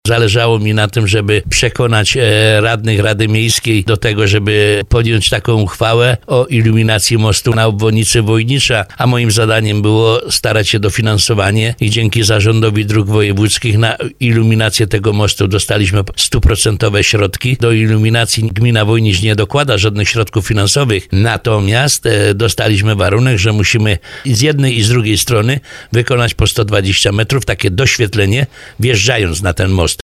Jak mówi burmistrz Tadeusz Bąk, samorząd Wojnicza otrzymał na ten cel dofinansowanie.